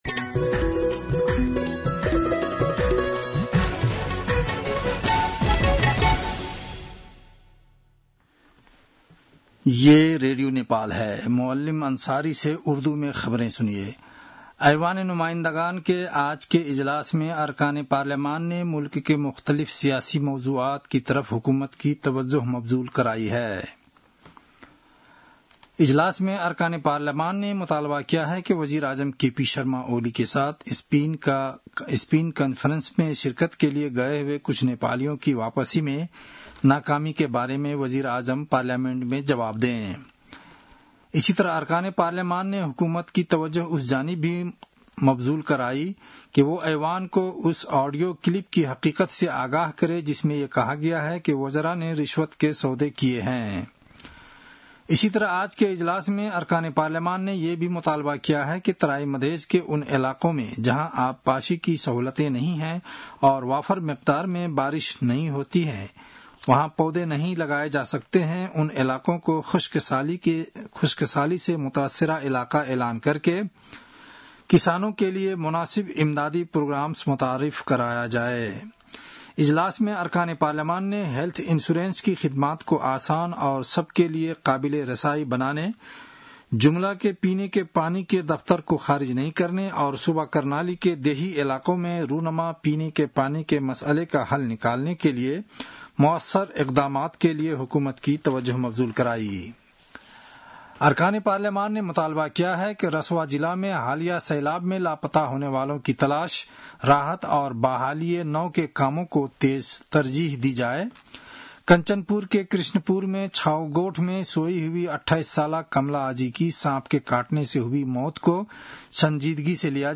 उर्दु भाषामा समाचार : २९ असार , २०८२